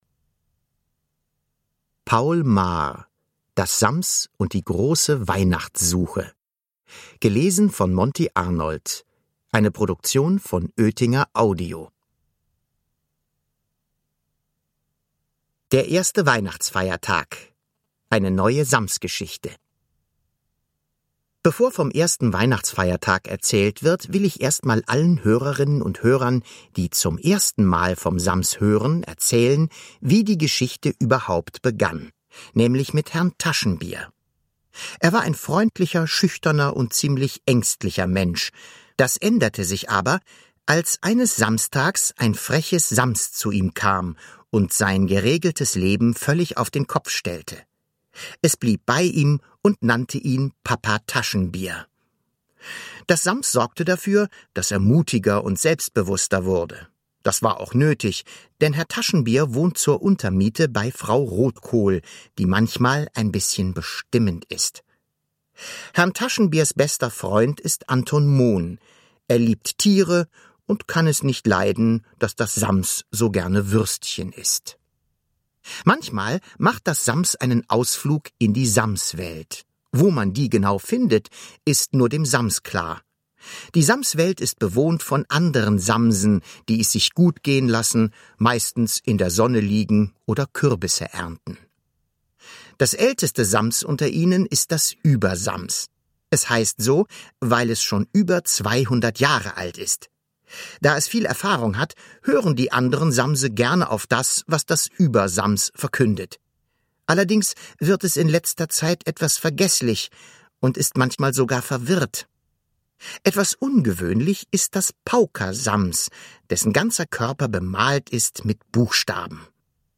Hörbuch: Das Sams 11.